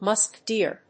アクセントmúsk dèer